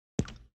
Звуки вареников
Звук вареника в сметане